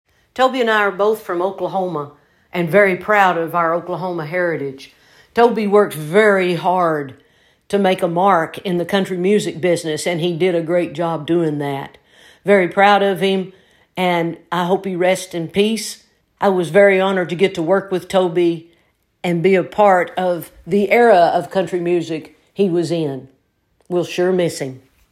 Reba McEntire shares her thoughts and remembrances about Toby Keith.